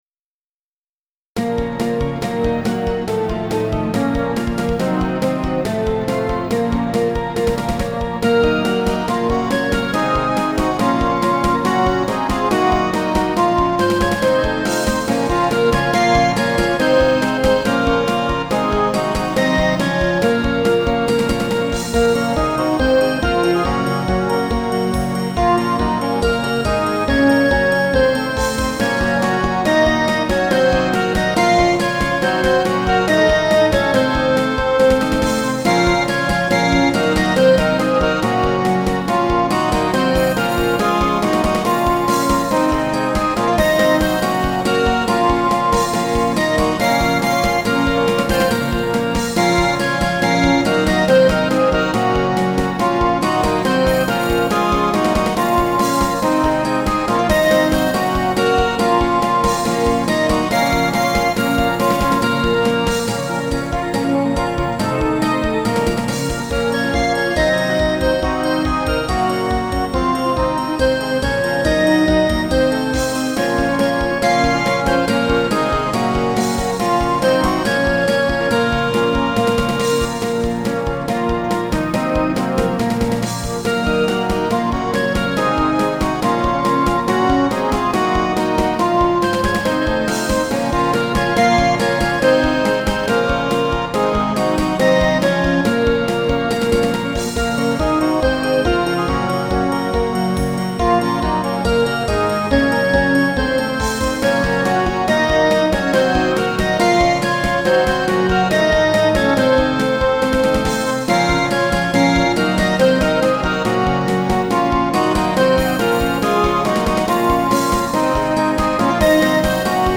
変ロ短調